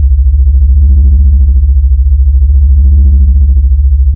tesla_idle.ogg